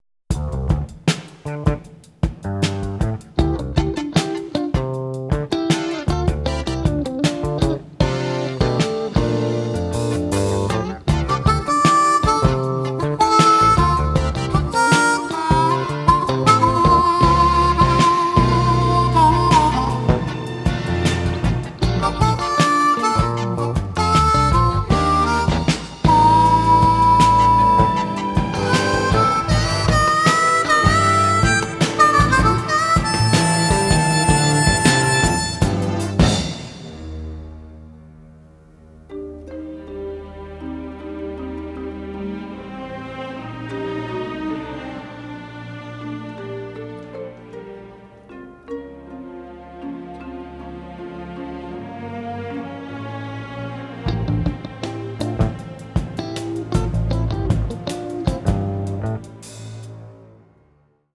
banjo
harmonica